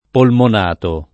[ polmon # to ]